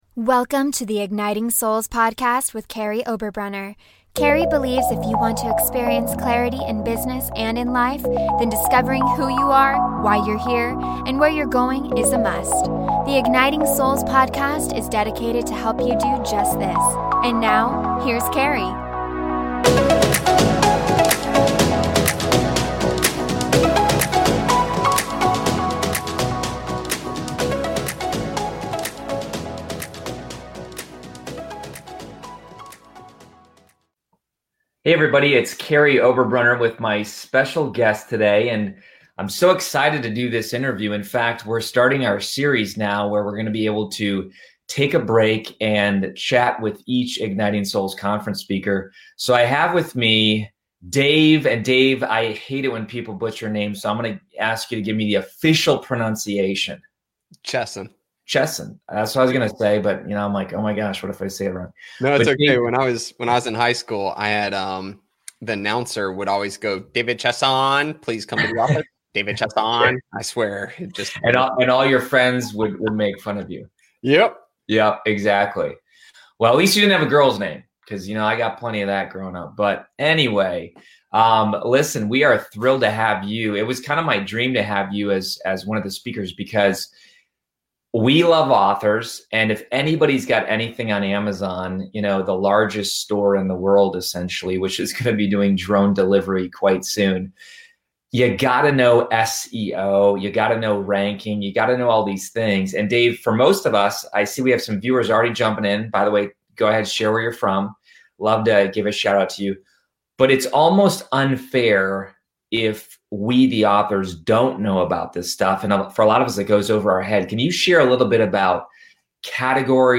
Exclusive Interview with Igniting Souls Conference Speaker